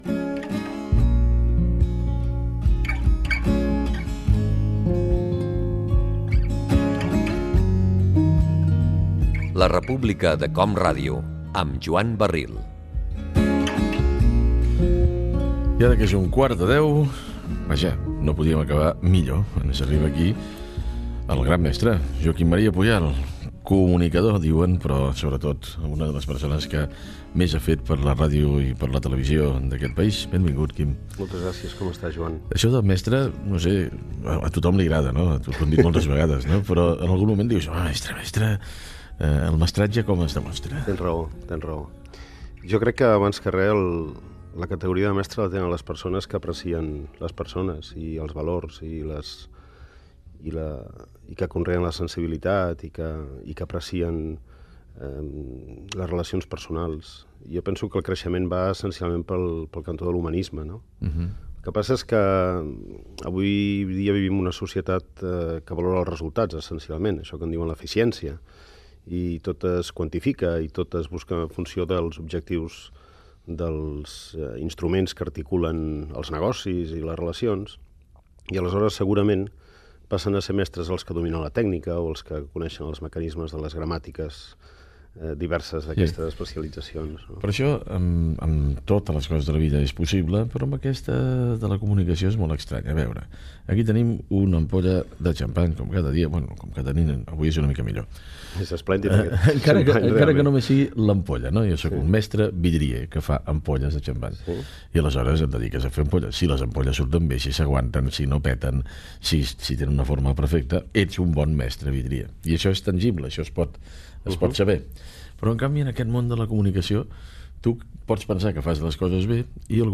hora i fragment d'una entrevista al periodista Joaquím Maria Puyal.
Info-entreteniment